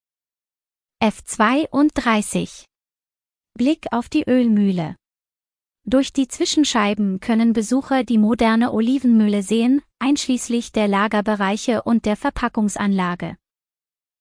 Geführte Audio-Tour